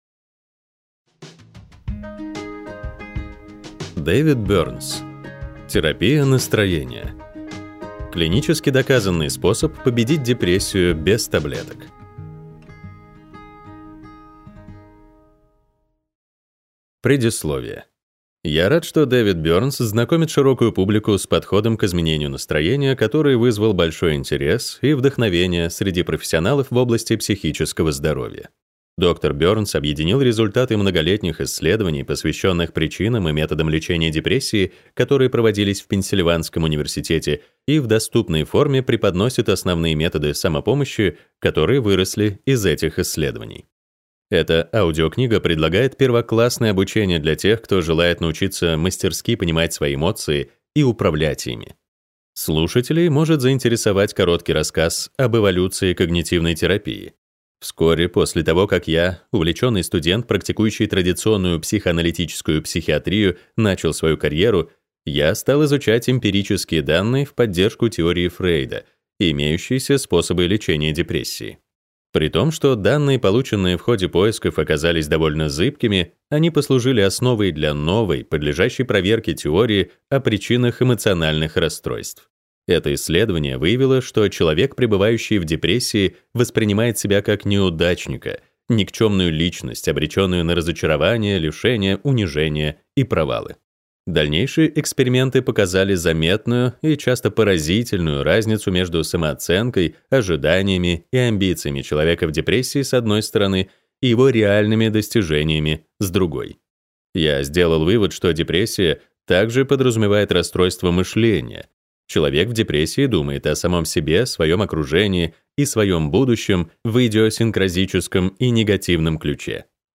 Аудиокнига Терапия настроения. Клинически доказанный способ победить депрессию без таблеток | Библиотека аудиокниг